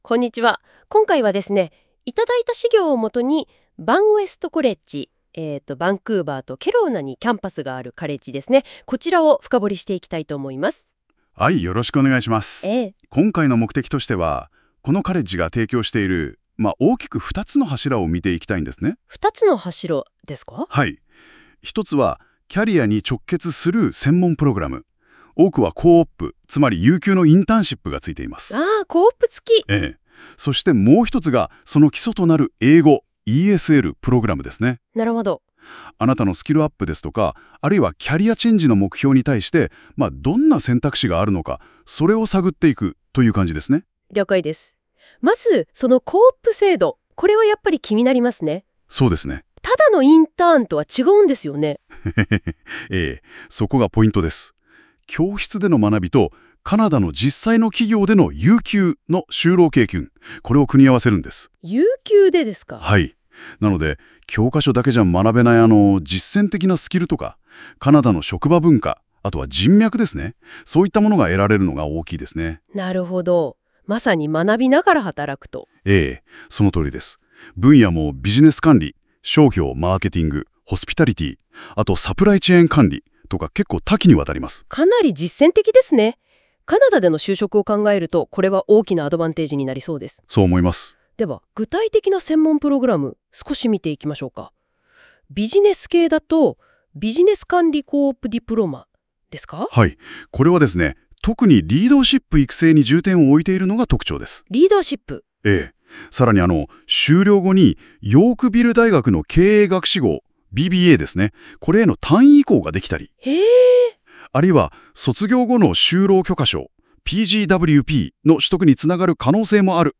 日本語による解説が聴けます